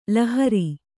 ♪ lahari